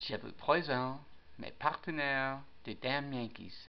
And so the Internet was getting more fun as well because we could put some audio files and so Tommy introduced every section of the web site with a greeting phrase in French!
Les fichiers sonores plus haut ont été enregistrés par Tommy dans son studio pour présenter chaque section du site.